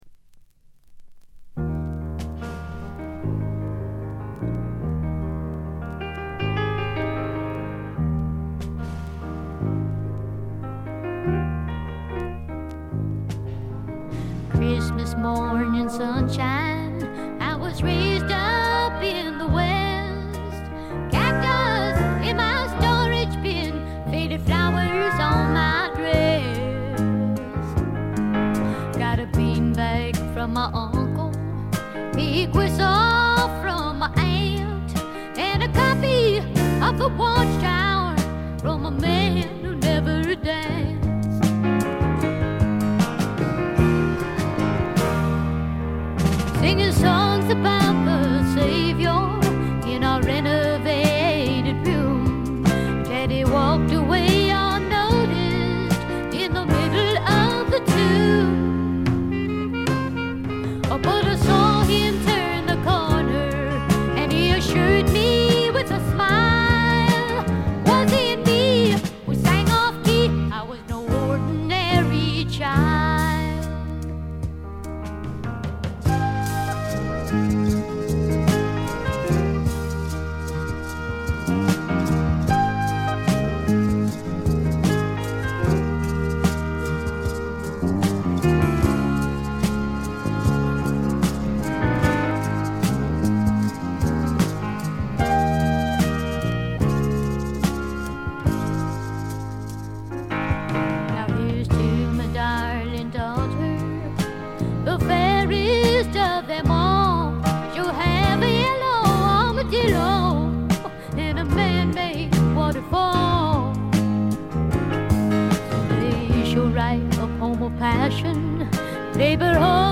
ごくわずかなノイズ感のみ。
全曲自作で良曲が並び、リリカルなピアノとコケティッシュなヴォーカルがとても良いです。
試聴曲は現品からの取り込み音源です。